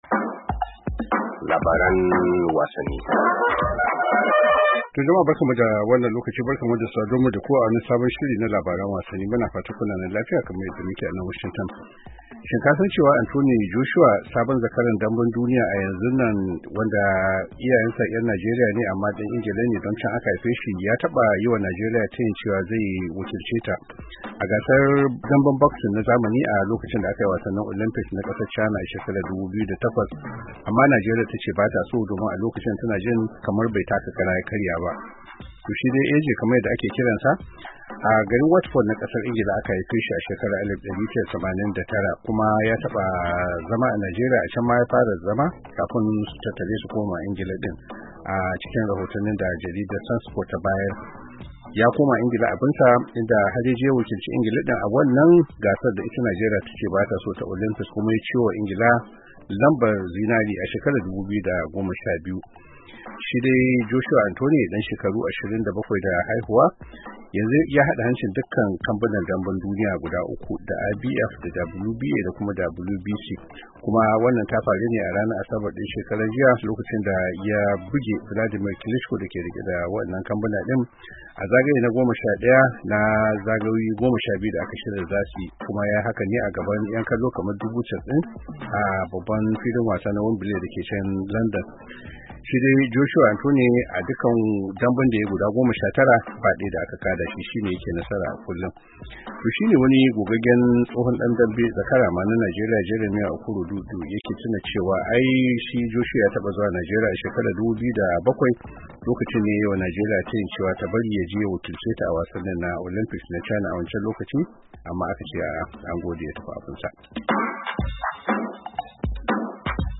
Ga dai ci gaba da tattaunawar